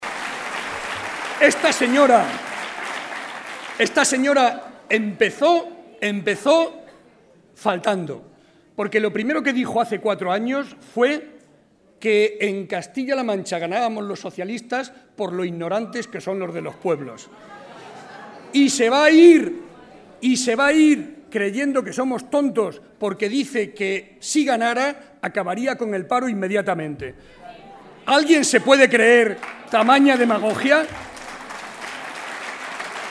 Audio Barreda mitin Toledo 2